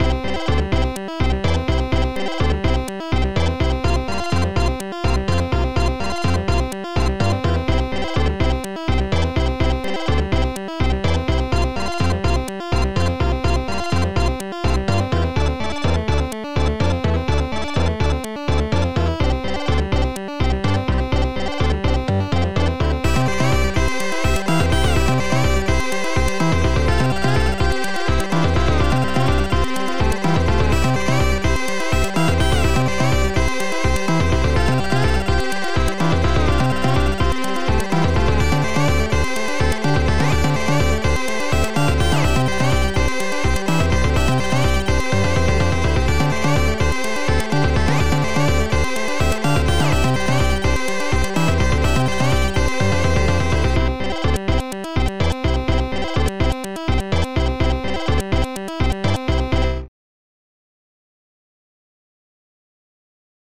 A C64-style tune
microtune compo.
Composed on the Amiga